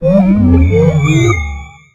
Audio / SE / Cries / MUSHARNA.ogg